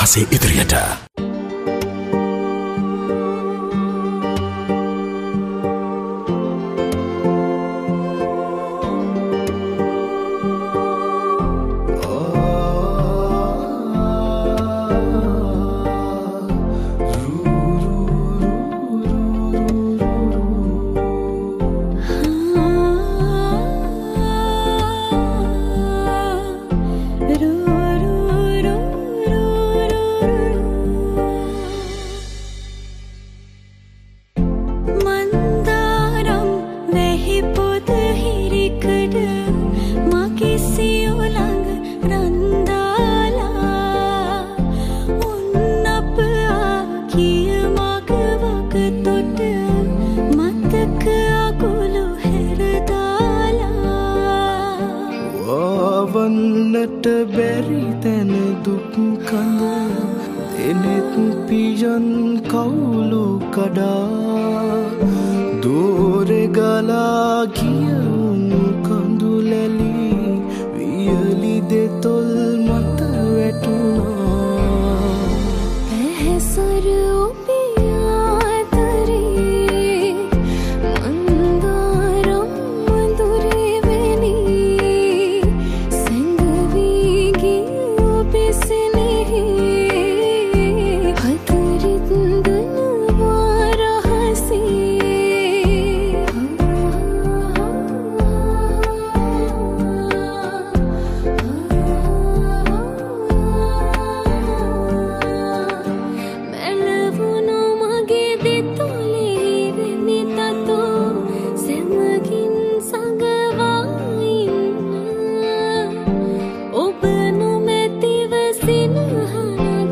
Group Song
reality show